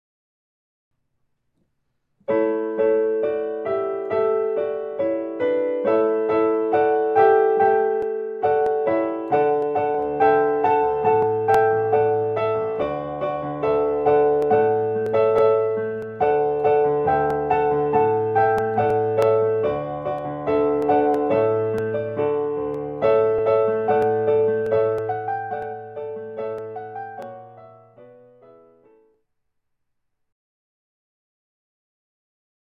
Classical Solo Piano